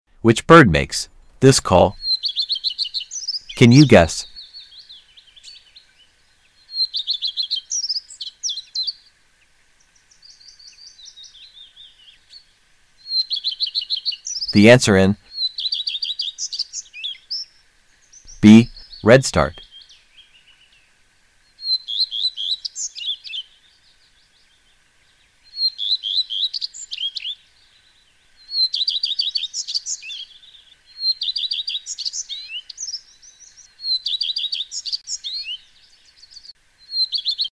Which bird makes this call..?..Can sound effects free download